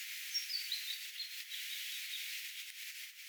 ilmeinen punarinnan vähän erikoisempi säe?
ilmeinen_punarinnan_vahan_erikoisempi_sae.mp3